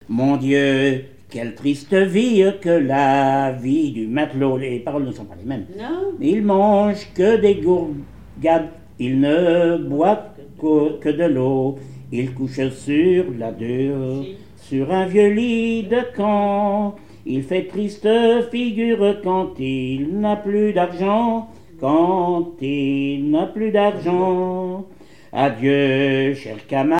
Localisation Noirmoutier-en-l'Île
Genre strophique
Catégorie Pièce musicale inédite